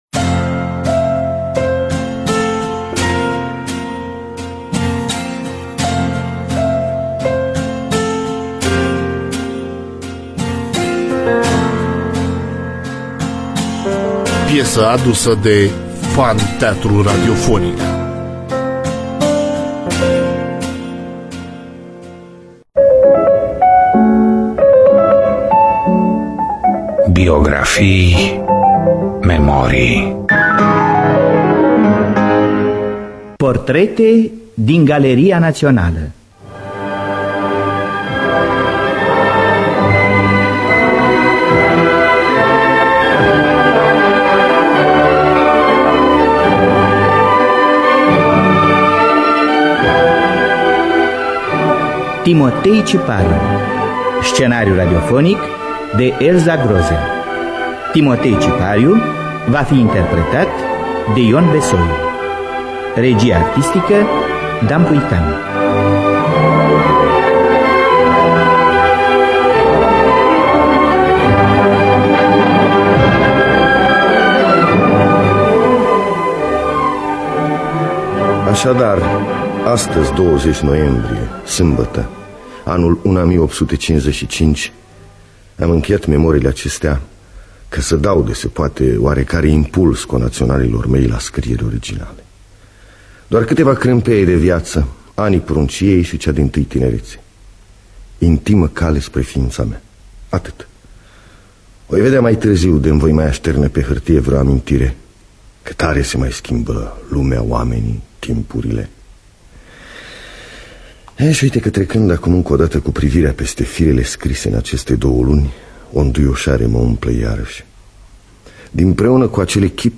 Biografii, Memorii: Timotei Cipariu (1980) – Teatru Radiofonic Online